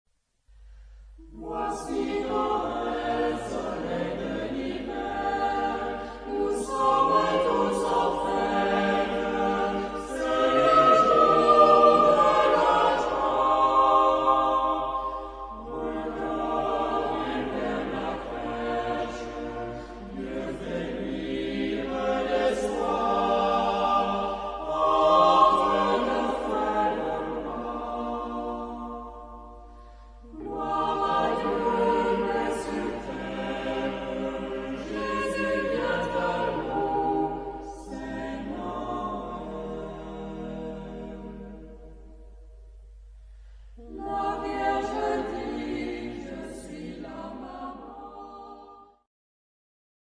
Genre-Stil-Form: Weihnachtslied ; geistlich
Chorgattung: SATB  (4 gemischter Chor Stimmen )
Tonart(en): Es-Dur